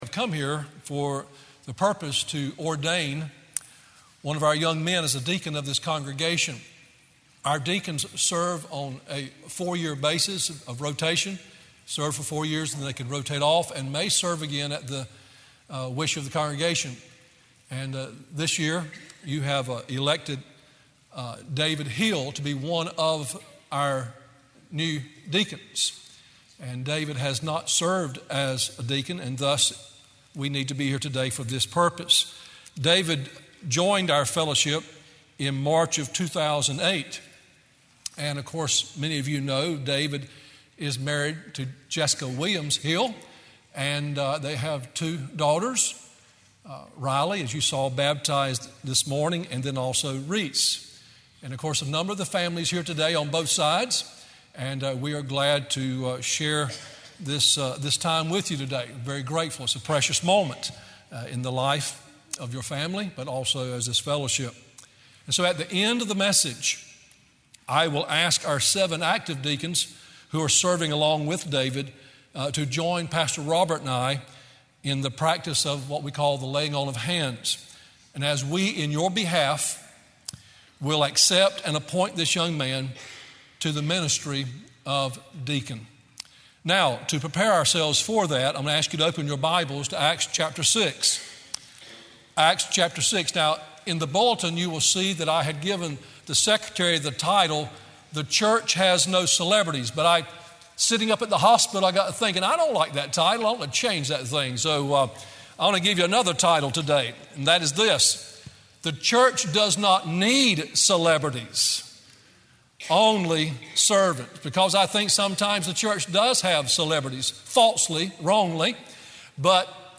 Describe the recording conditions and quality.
Subscribe » October 27 2019 Series: Self-Evident Truths - Message #1 We Love Our Valuables Morning Worship Matthew 6:19-21 1.